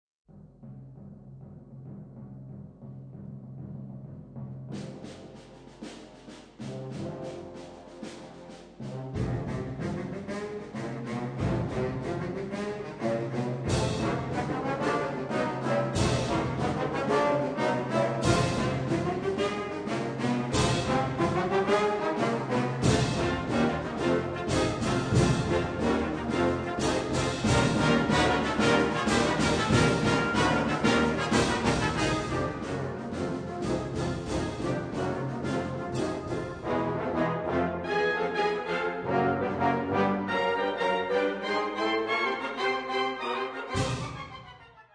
Categorie Harmonie/Fanfare/Brass-orkest
Bezetting Ha (harmonieorkest)